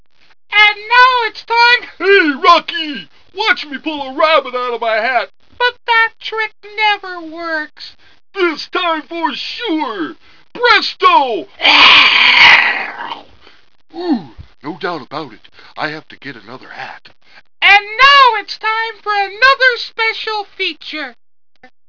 My impression of: "Bullwinkle's Hat Trick."